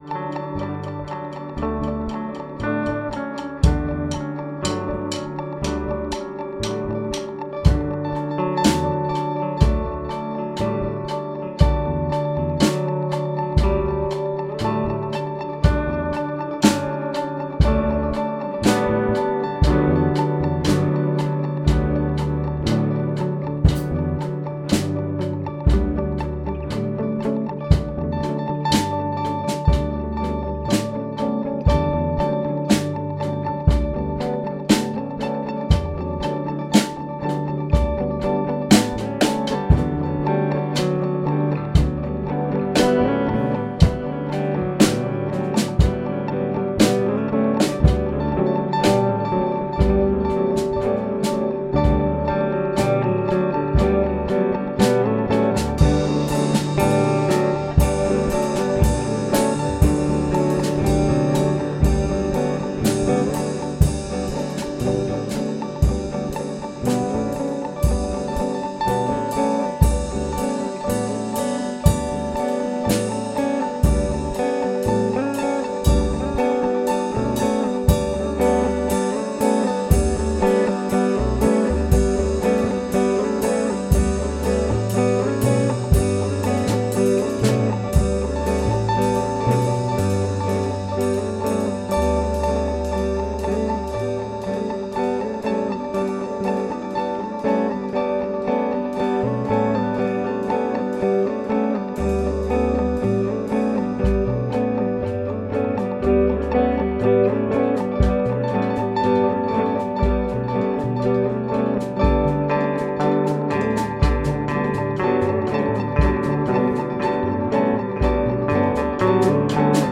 guitar, drums, bass. 3 bests
I can safely say the first two are clean.
guitarcore_2_of_3_mix.mp3